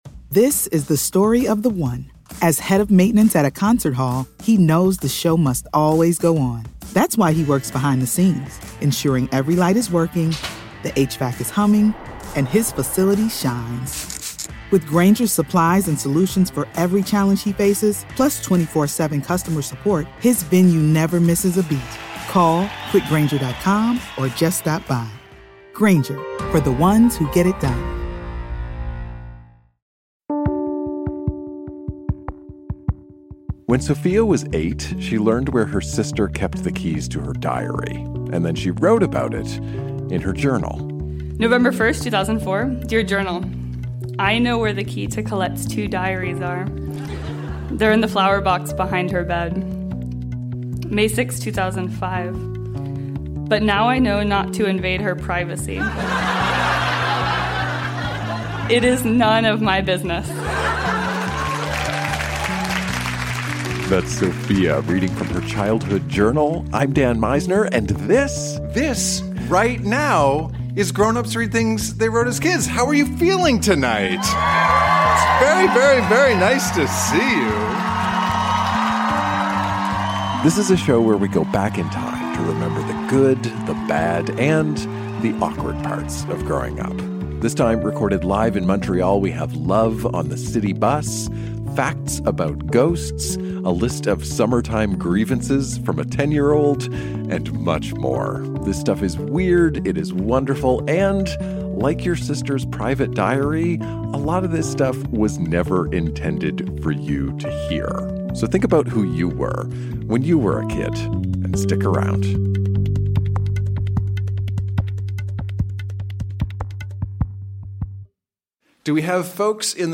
Recorded live at La Sala Rossa in Montréal.